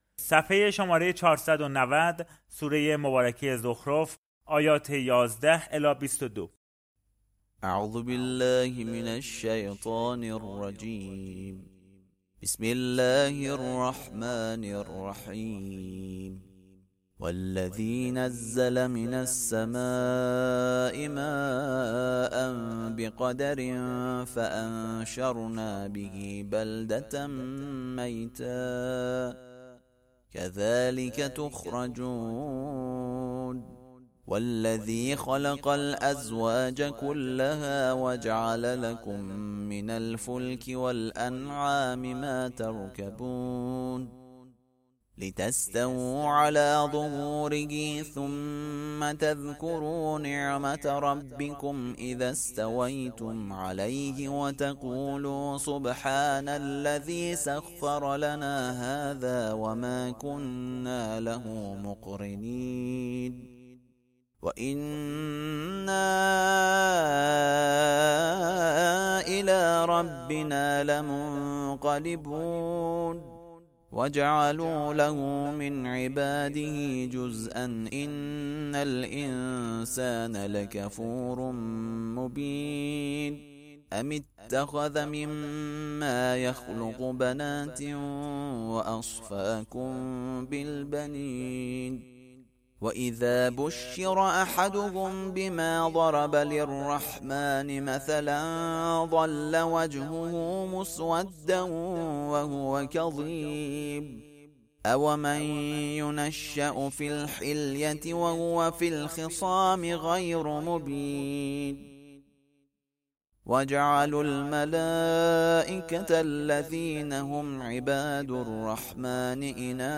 ترتیل صفحه ۴۹۰ سوره مبارکه زخرف(جزء بیست و پنجم)
ترتیل سوره(زخرف)